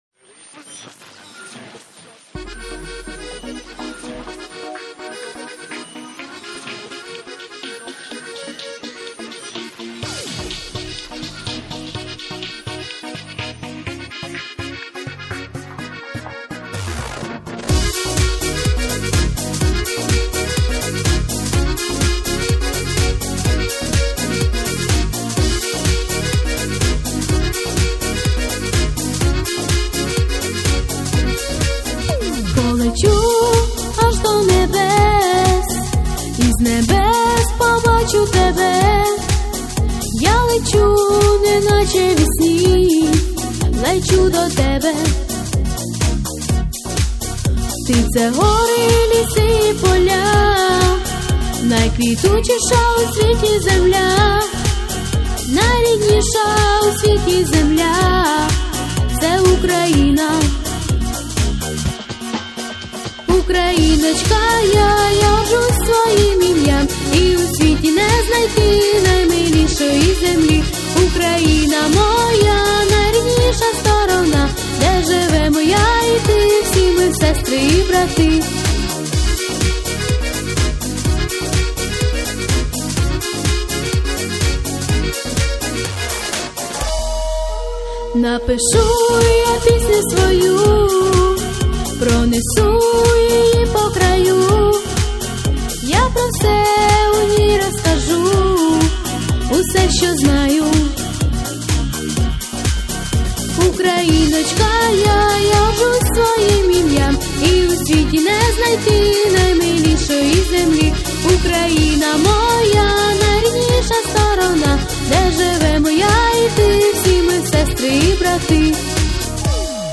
Плюсовий запис